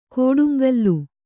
♪ kōḍuŋgallu